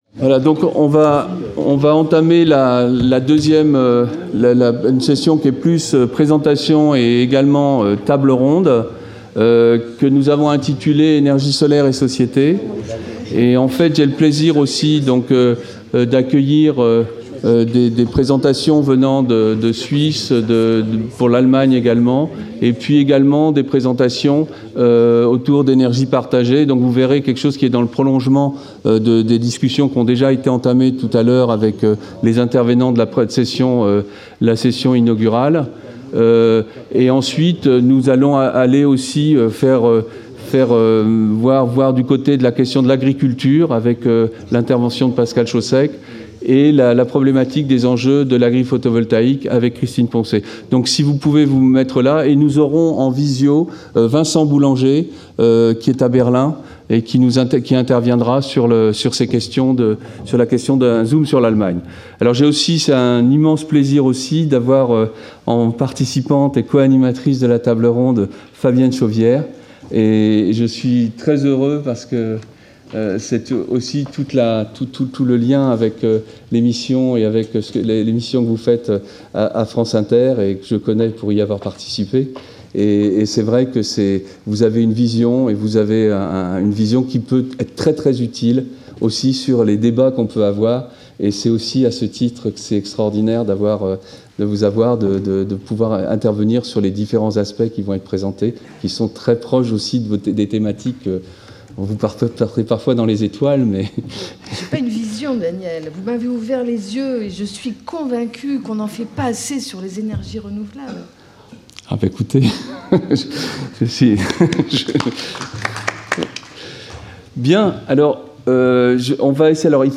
Round table : Solar energy and society | Collège de France